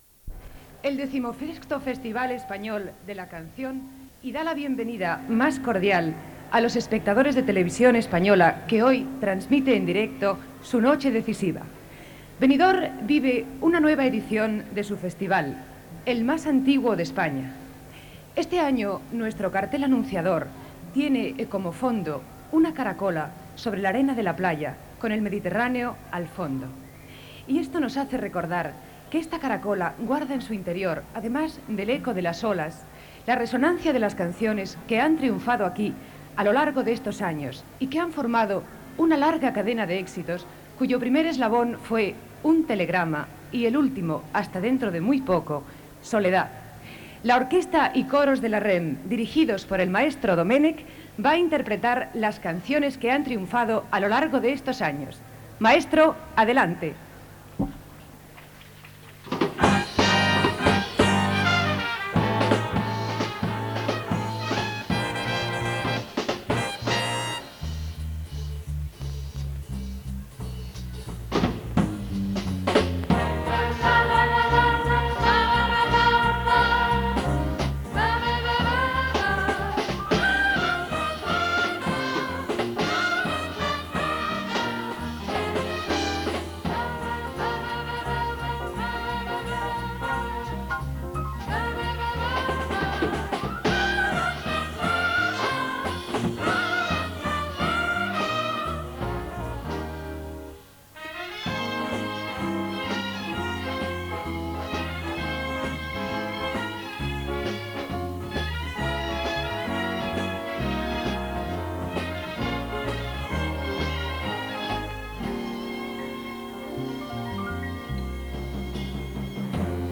Transmissió del XVI Festival de Benidorm. Presentació, l'Orquesta de la REM interpreta les cançons guanyadores en anys anteriors, comentari sobre les emissores de la Cadena REM-CAR, llista de les cançons i intèrprets i primera cançó participant "Manuela"
Musical